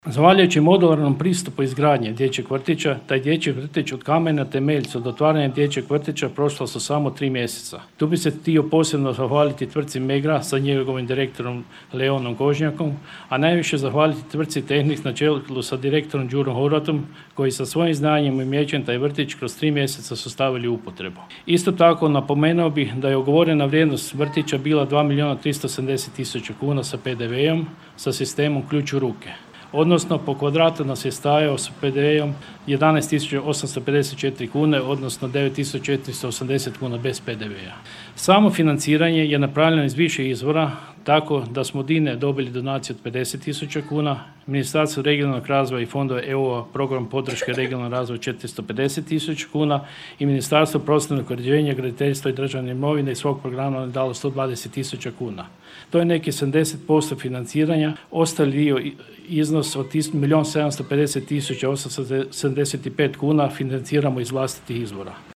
Investicija je to koja je uložena u sretno odrastanje 120 mališana koliko će ih u novoj pedagoškoj godini polaziti vrtić, čulo se na svečnosti otvaranja koja je upriličena u srijedu u prijepodnevnim satima.
Od kamena temeljca do otvaranja trebala su samo tri mjeseca, istaknuo je načelnik Miljenko Horvat: